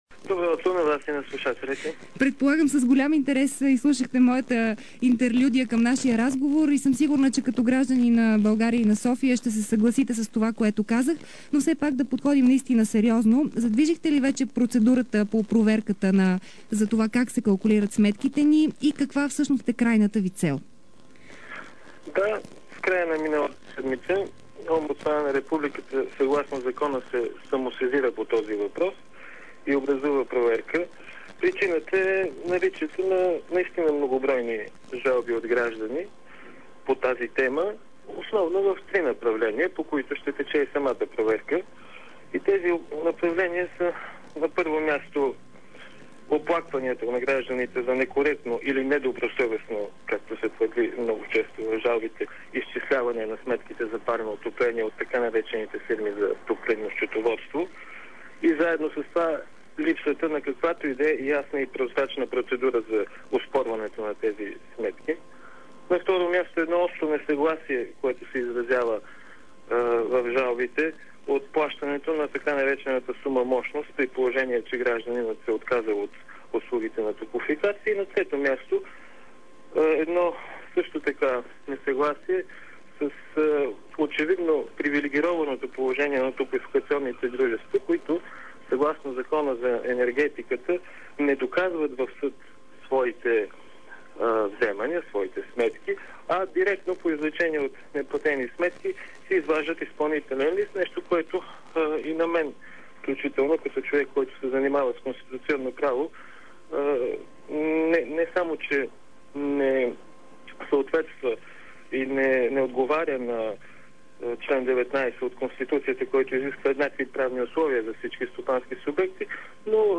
Борислав Цеков, заместник-омбудсман на България за неправилно калкулирани сметки за парно в интервю за предаването „Дарик кафе”